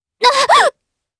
Chrisha-Vox_Damage_jp_03.wav